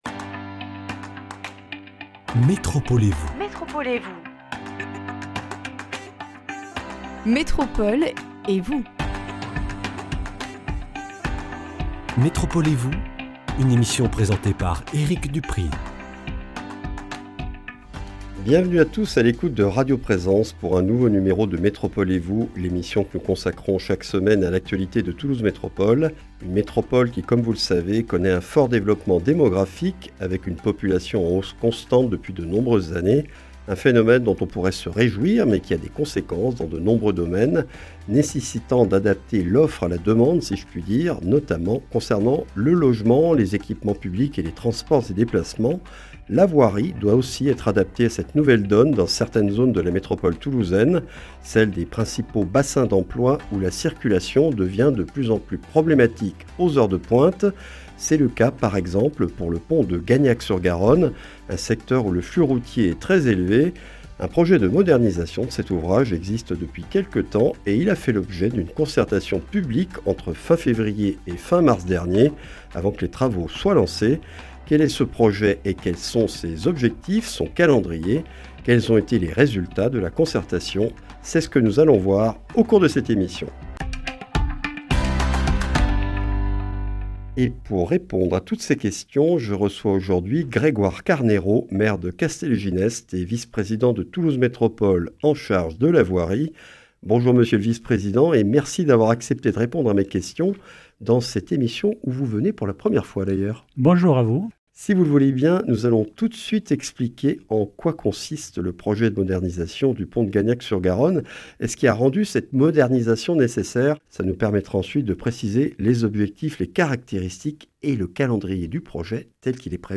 Grégoire Carneiro, maire de Castelginest et vice-président de Toulouse Métropole chargé de la voirie, présente les caractéristiques et objectifs de ce projet, puis commente les résultats de cette concertation qui a recueilli 764 contributions.